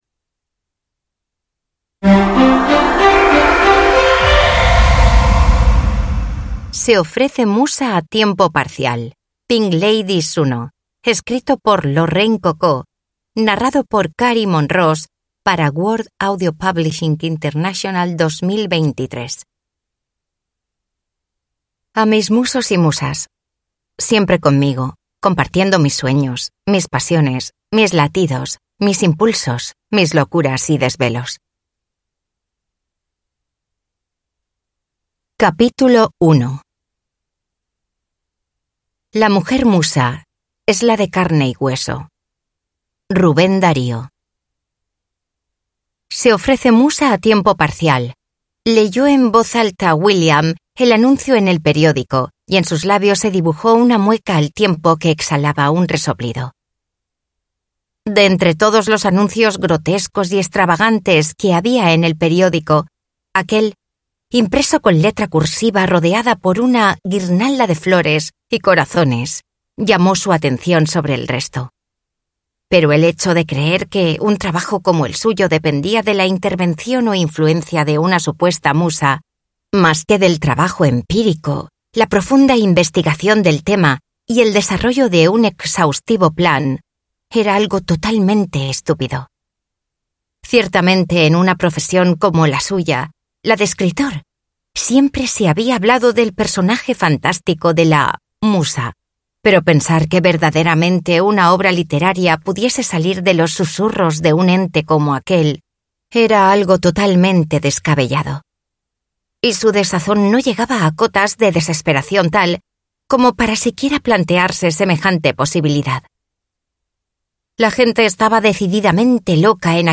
Se ofrece musa a tiempo parcial (ljudbok) av Lorraine Cocó